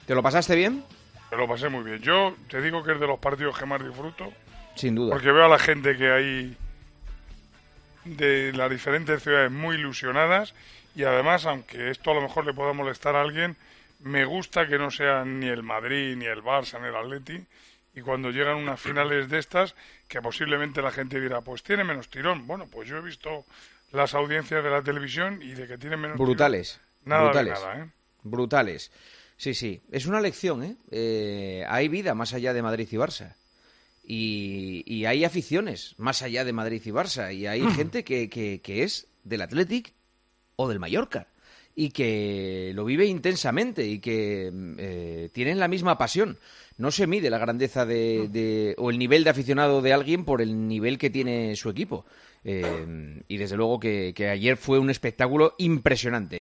En el Tertulión de los domingos de Tiempo de Juego comenzamos hablando de la gran final jugada en Sevilla entre el Athletic y el Mallorca y Manolo Lama elogió que el trofeo se lo llevara un equipo que no fuera de los tres grandes: "Es de los partidos que más disfruto, veo a la gente muy ilusionada y, aunque le moleste a alguien, me gusta que no gane ni el Madrid, ni el Barça ni el Atleti. La gente dirá que tiene menos tirón pero nada de nada".
Con Paco González, Manolo Lama y Juanma Castaño